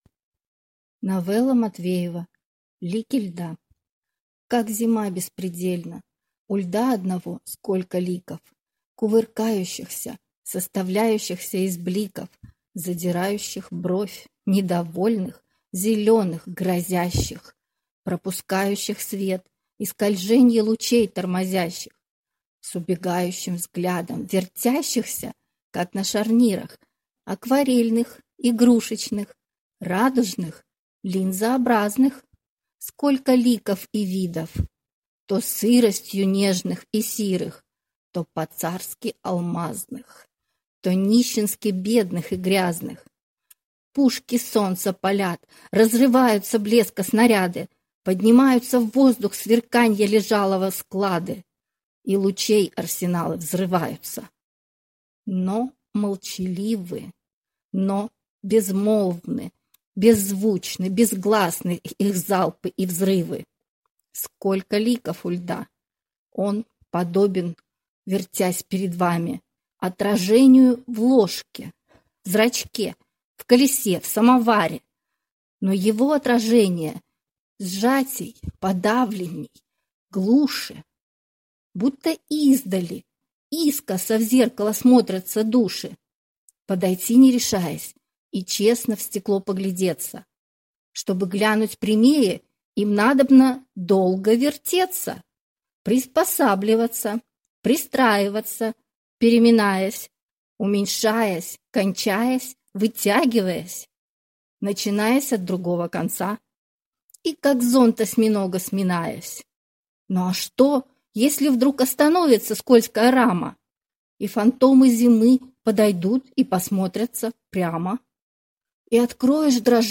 Комментарий инициатора: Без мелодии, без вокала, без музыки. Только голос. Только поэзия.
Да, любопытный формат ...голос не певческий, а разговорный когда еще услышишь))